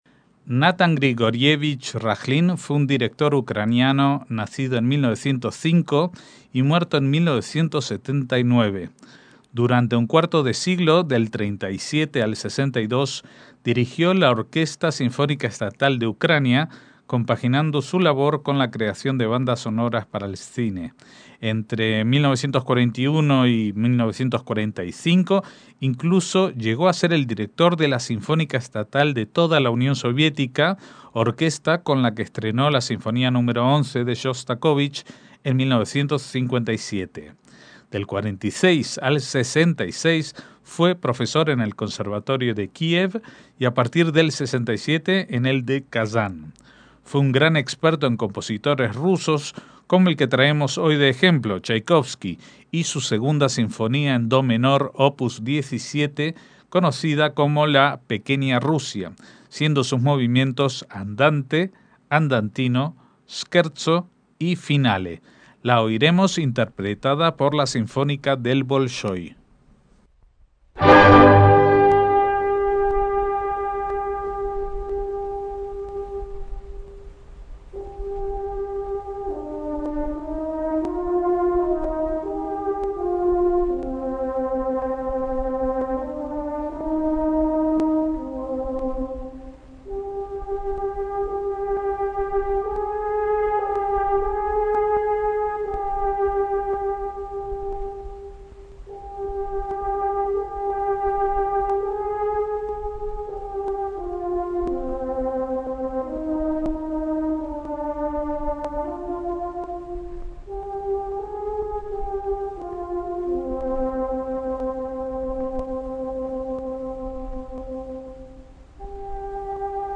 MÚSICA CLÁSICA - Natan Rakhlin (1906 – 1979) fue un director de orquesta judío soviético y ucraniano.
Lo dirigiendo la Sinfonía Nº2 en do menor Op. 17 ("La Pequeña Rusia") de Chaikovsky, cuyos movimientos son Andante, Andantino, Scherzo y Finale